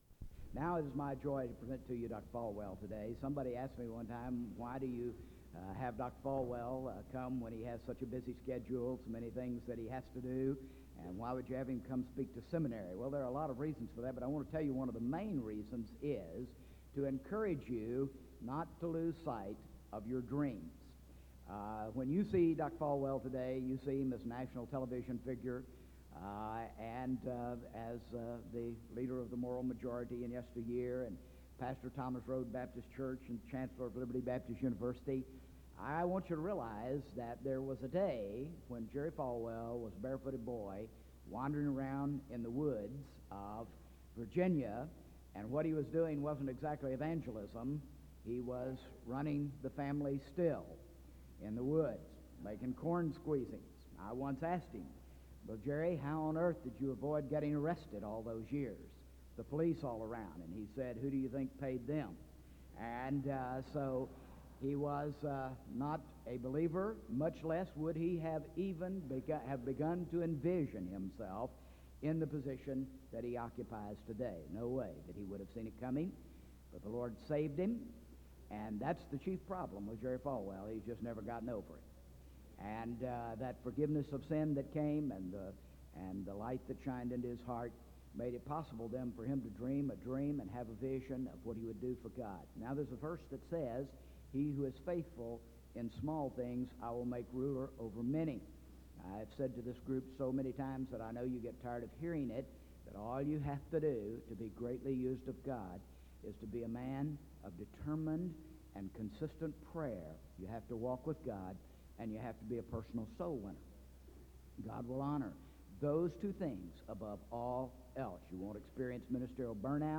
SEBTS Chapel - Jerry Falwell April 25, 2000
In Collection: SEBTS Chapel and Special Event Recordings SEBTS Chapel and Special Event Recordings - 2000s Miniaturansicht Titel Hochladedatum Sichtbarkeit Aktionen SEBTS_Chapel_Jerry_Falwell_2000-04-25.wav 2026-02-12 Herunterladen